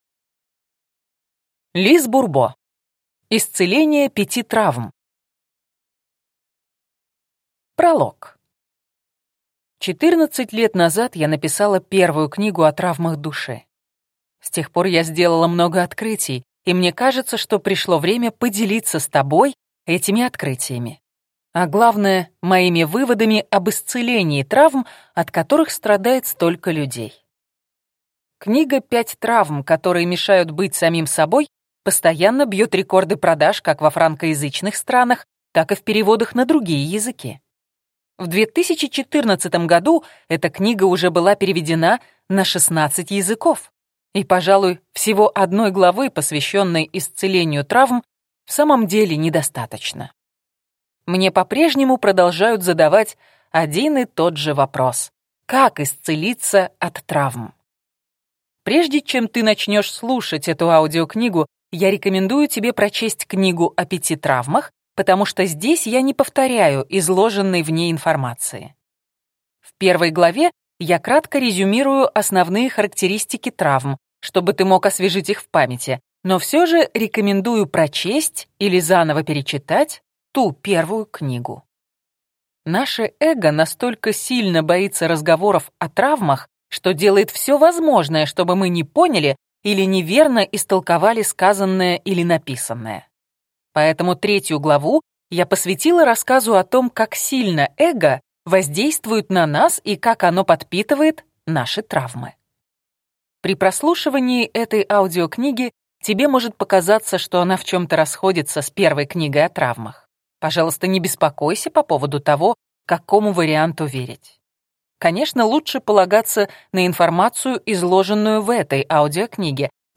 Аудиокнига Исцеление пяти травм | Библиотека аудиокниг